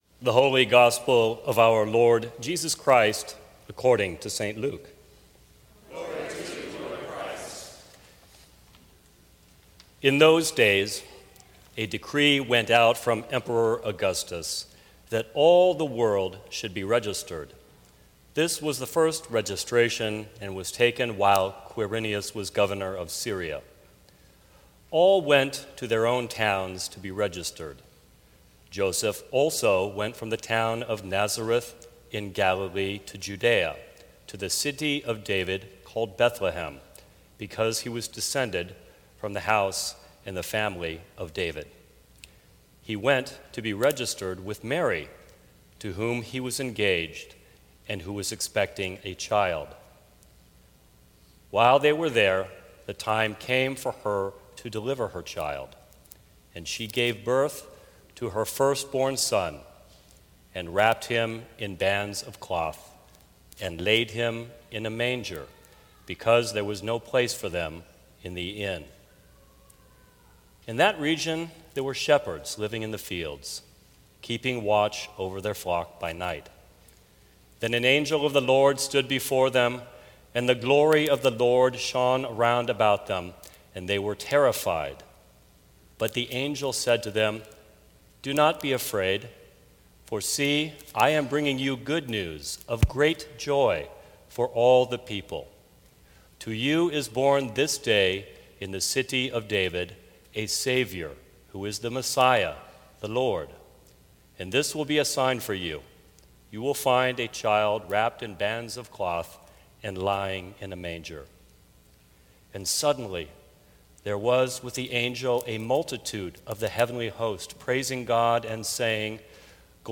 Sermons from St. Cross Episcopal Church Joyful Reunion Dec 25 2016 | 00:11:38 1x 00:00 / 00:11:38 Subscribe Share Apple Podcasts Spotify Overcast RSS Feed Share Link Embed